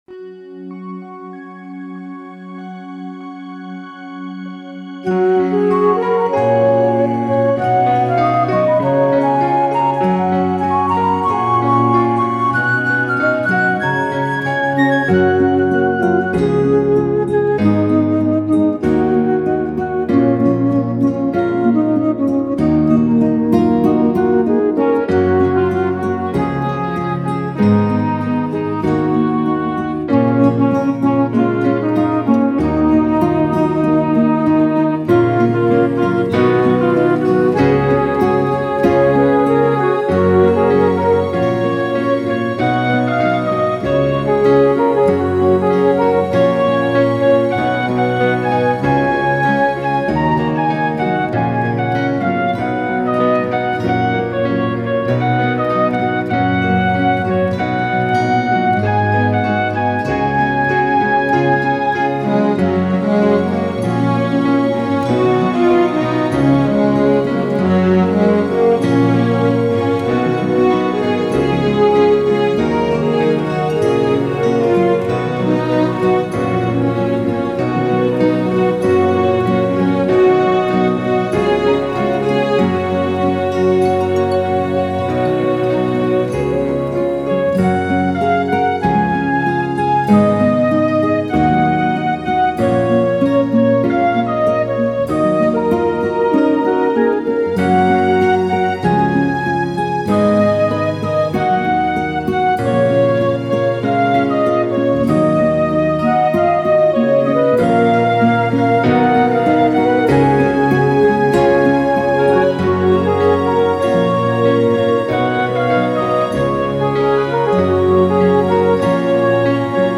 Meant to Me Instrumental 2014
meant-to-me-3-instrumental-w-gtr-1-gain_01_lmmaster.mp3